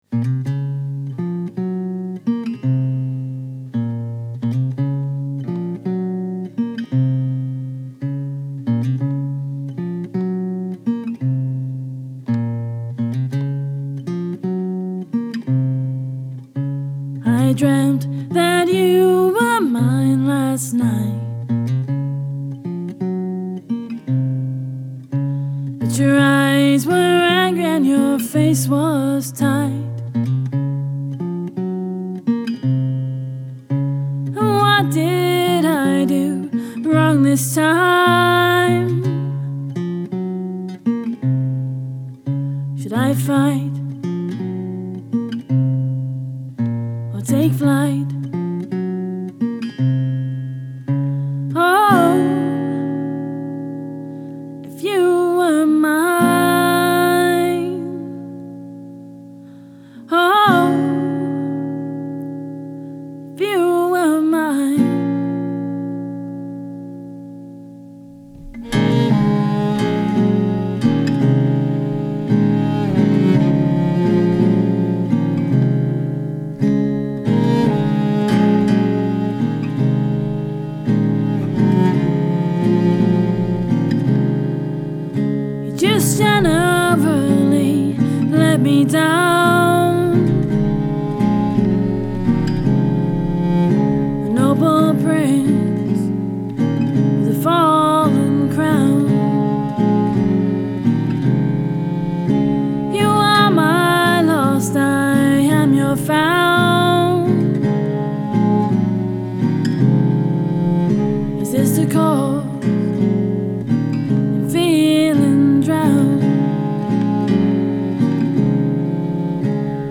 this track was the final one that we recorded whilst doing overdubs at my house; Track info; Vocals - CK7 in to Focusrite ISA220 Acoustic Guitar - Apex 205 (1 1/2 foot back, pointed at neck join) in to ISA220, CK7 (cardioid, 1 1/2 foot back, pointed just below the saddle) in to TLA 5001 Cello - Apex 205 into TLA 5001 (4 foot back pointed at neck join), CK7 into ISA220 (fig.8, at bow height to the left of the cello if looking at it, aimed just off of "F hole") tbh this was one of those tracks where i've actually had to do very little in terms of EQ, compression etc to make it work. the same medium plate reverb on everything in varying amount off of sends (Altiverb 6, EMT 140 ).
there has been some eq, compression, and reverb applied, and this is the sound of this mic on these sources in this space.
the CK7 is much smoother and much sweeter in the higher end.